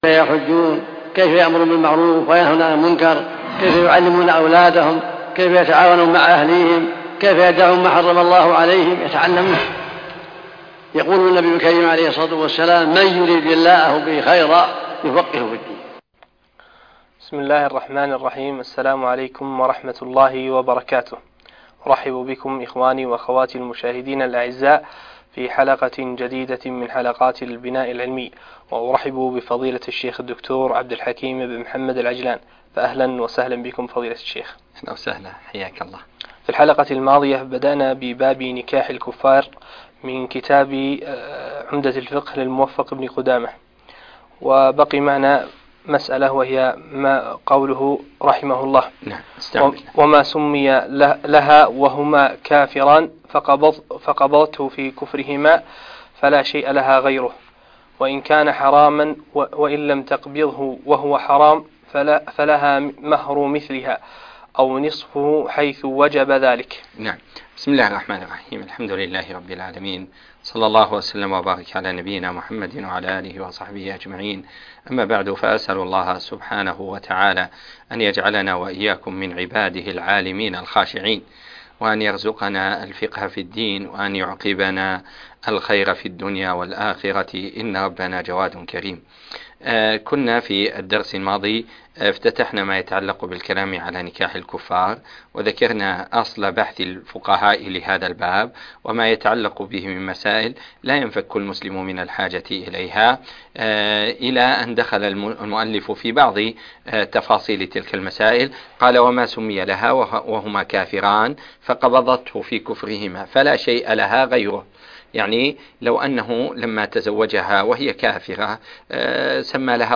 الدرس العاشر - عمدة الفقه (5)